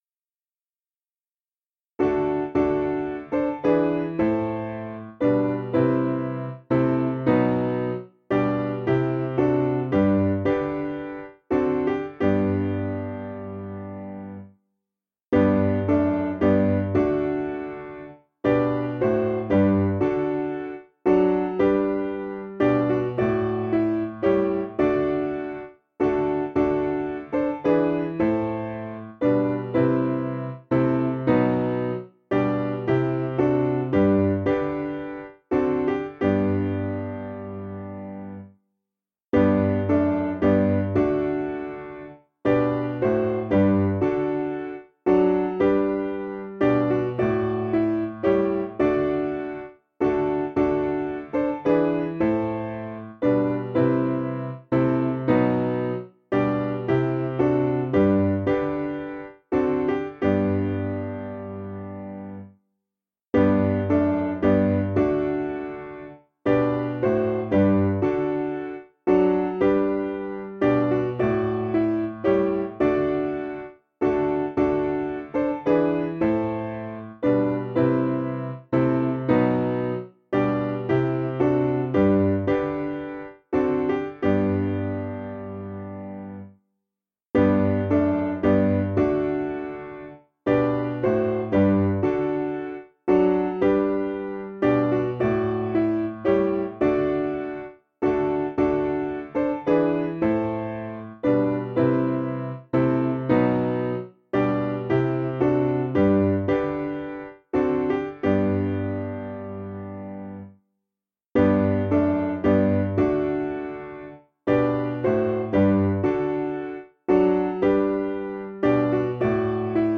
Tune: BINCHESTER
Key: F Major